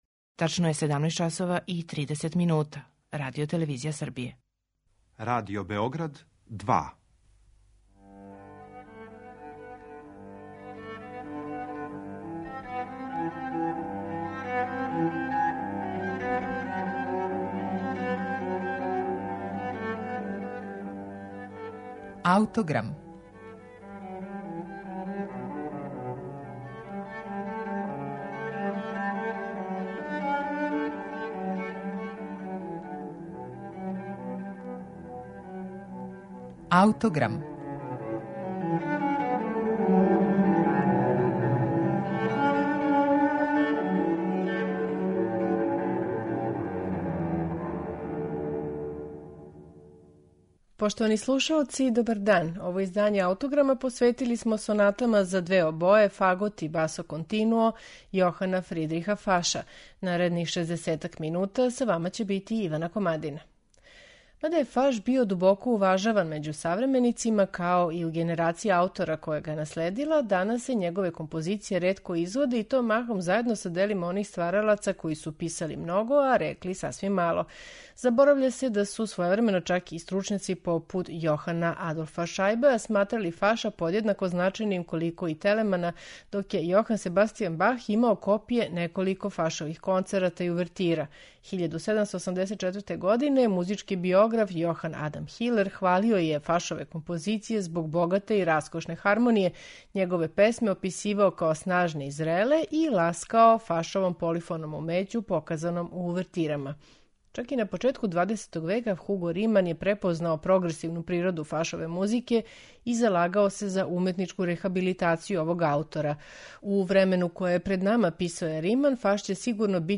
Представићемо Сонате за две обое, фагот и басо континуо, Јохана Фридриха Фаша
виола да гамба
чембало